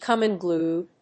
アクセントcòme unglúed